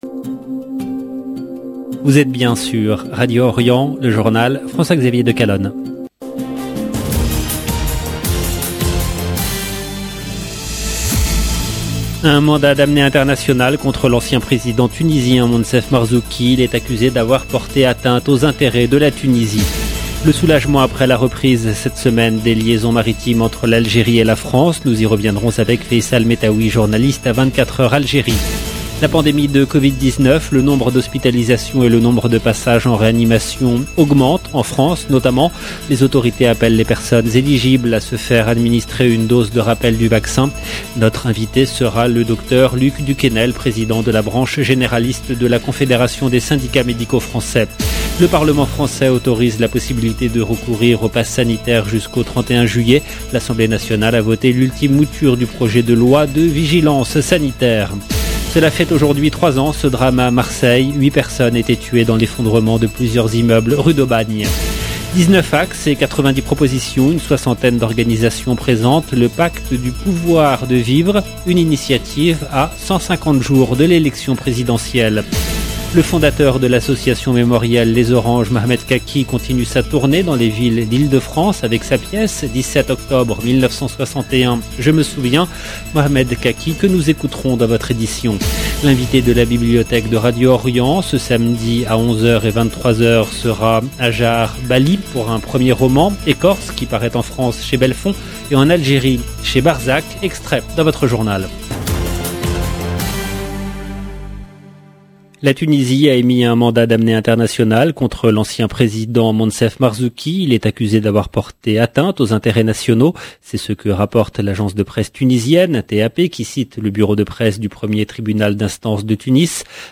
EDITION DU JOURNAL DU SOIR EN LANGUE FRANCAISE DU 5/11/2021